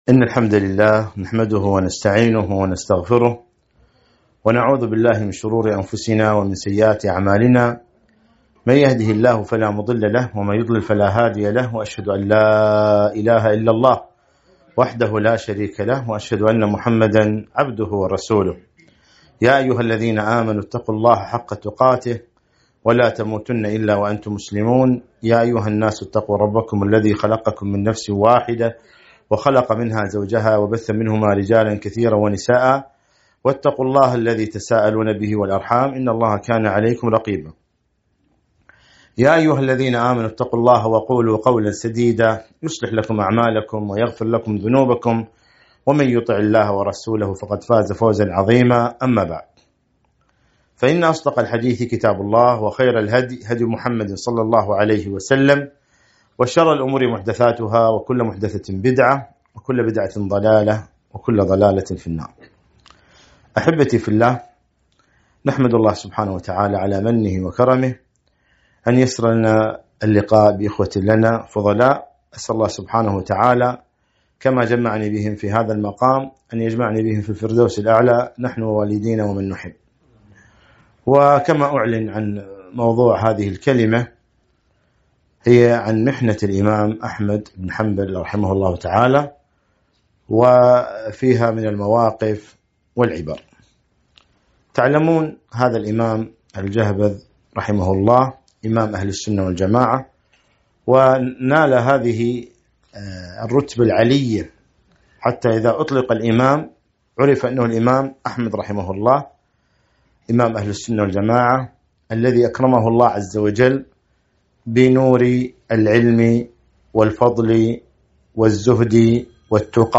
محاضرة - محنة الإمام أحمد بن حنبل وقفات وعبر